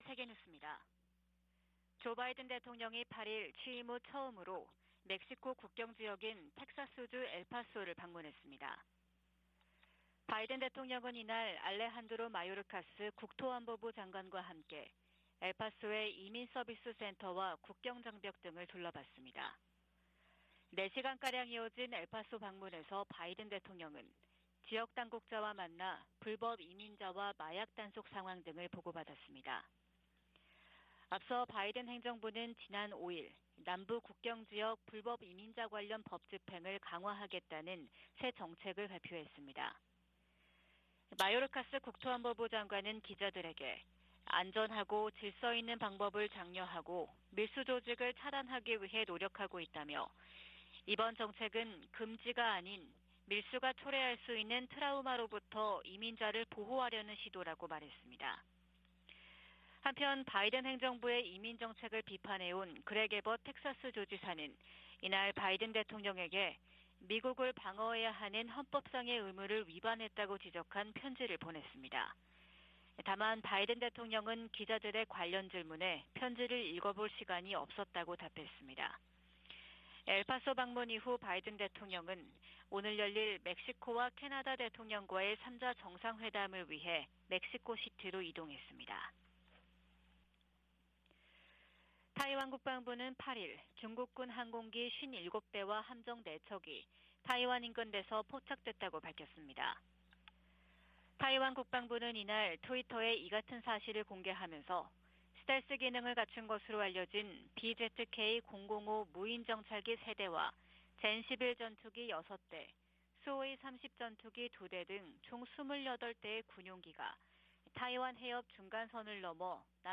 VOA 한국어 '출발 뉴스 쇼', 2023년 1월 10일 방송입니다. 미 국무부는 6년째 공석인 북한인권특사 임명 여부와 관계 없이 미국은 북한 인권 문제에 집중하고 있다고 밝혔습니다. 미국의 한반도 전문가들은 실효성 논란에 휩싸인 9.19 남북 군사합의와 관련해, 이를 폐기하기보다 북한의 도발에 대응한 ‘비례적 운용’이 더 효과적이라고 제안했습니다.